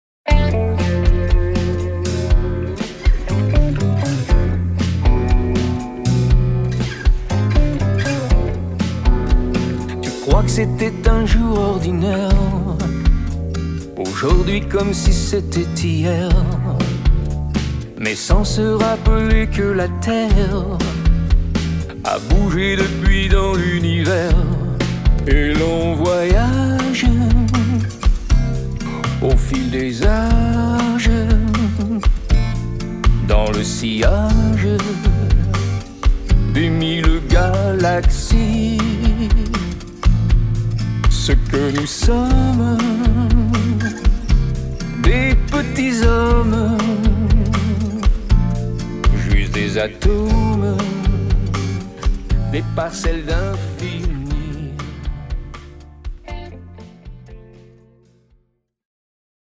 exprimées en ballades, jazz, bossa-novas, pop